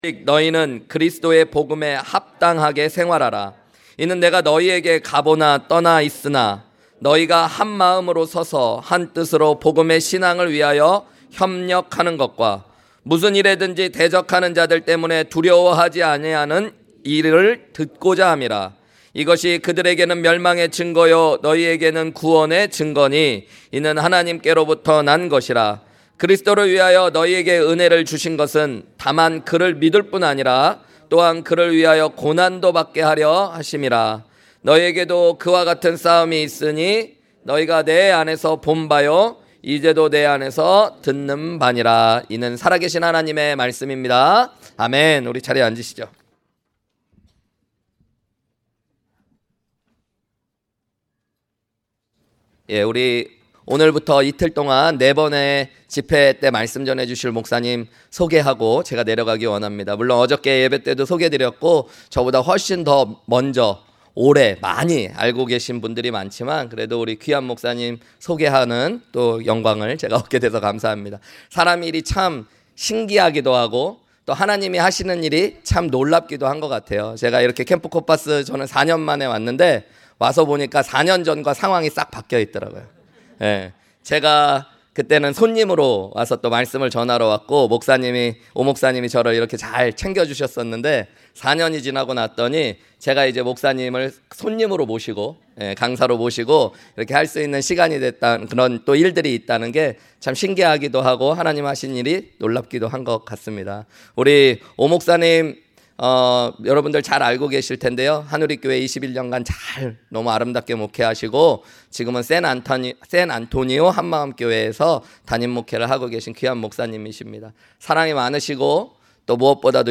주일 외 설교
2022 H-Camp 첫째날 토요일 오후 설교